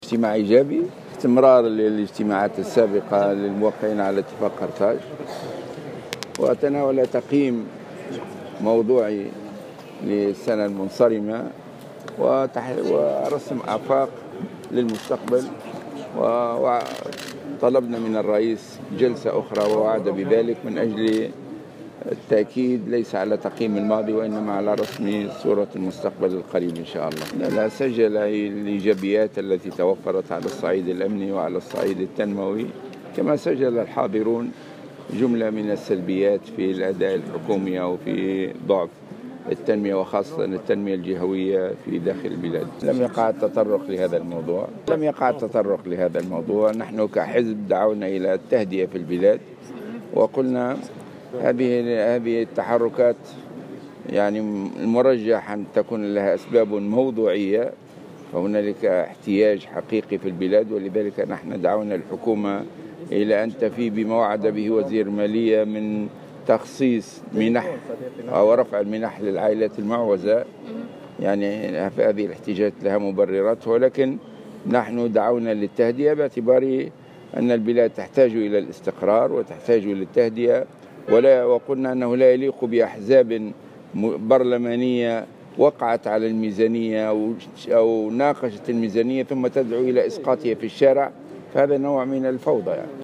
وأضاف الغنوشي في تصريح لمراسل الجوهرة اف ام، أن رئيس الجمهورية سجل الايجابيات خلال 2017 في الصعيد التنموي والأمني، كما سجل بعض الحاضرين سلبيات السنة الماضية خاصة بخصوص التنمية في الجهات.